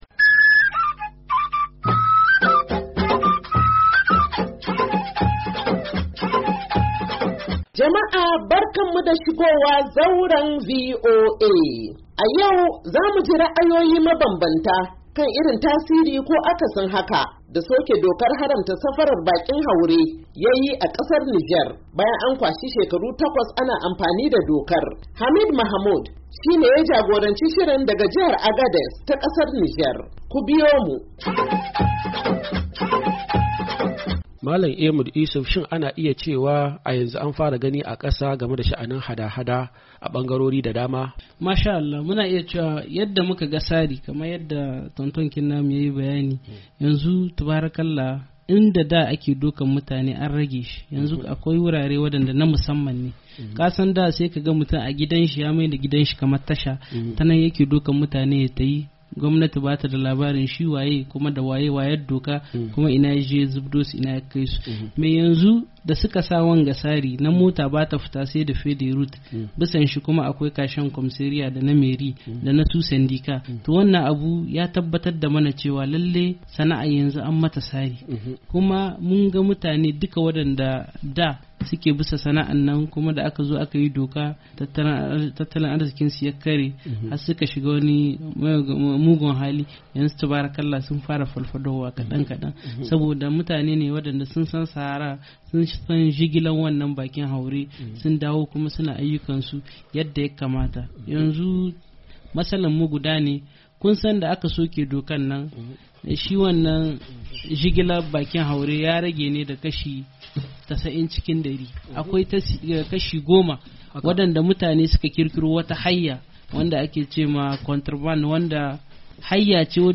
Shirin Zauran VOA na wannan mako, zai kawo muku ci gaban tattaunawa da bakin Zauran kan soke dokar haramta safarar bakin haure da aka yi a kasar Nijar.